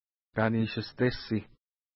ID: 266 Longitude: -62.5045 Latitude: 56.0479 Pronunciation: ka:ni:ʃəstesi: Translation: Double Lake (small) Feature: lake Explanation: The lake is formed of two equal and similar parts, each looking like a lake in itself.